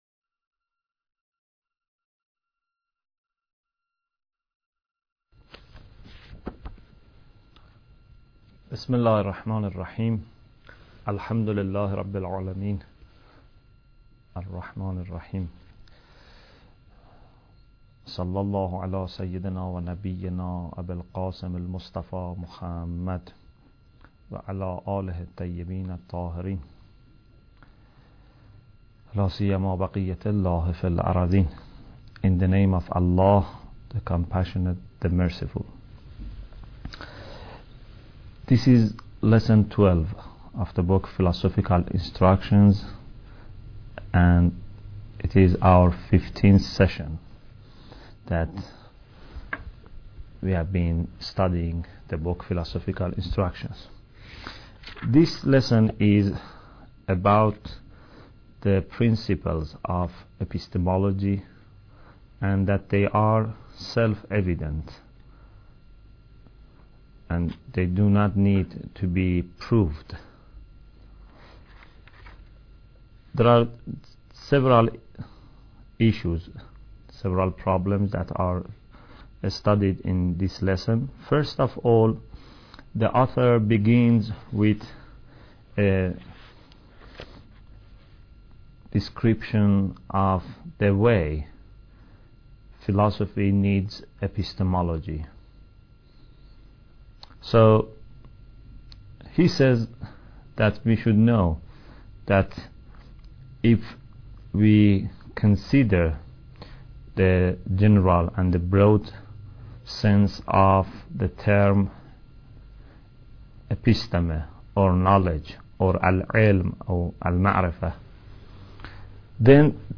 Bidayat Al Hikmah Lecture 15